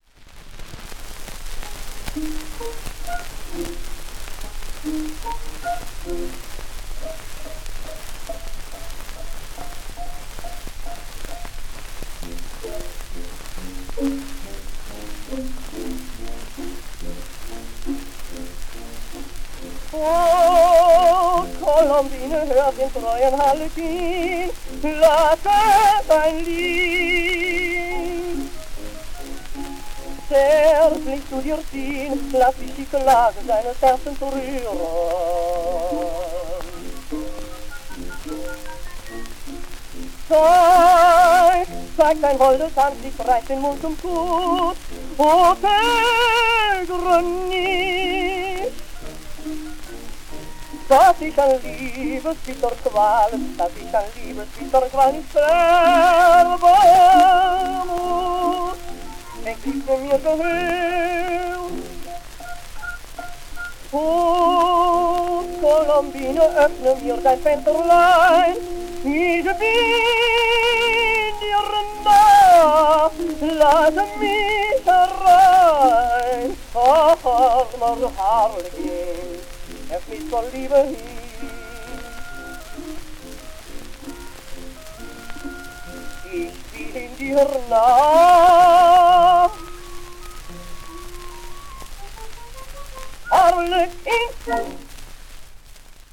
Those recordings were made in Poznań, then part of Germany and called Posen, in a small group of matrices probably recorded by members of the local theater